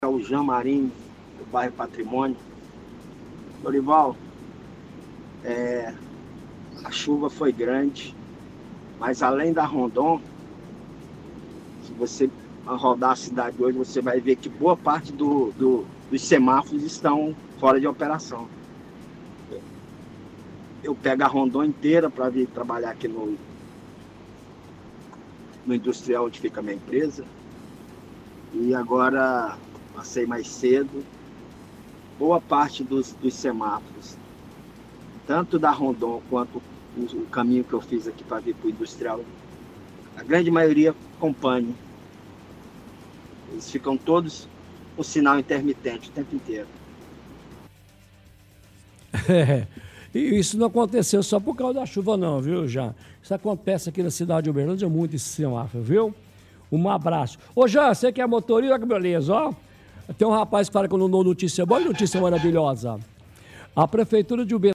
– Ouvinte fala que apesar da chuva, há algum tempo vários semáforos estão com problemas.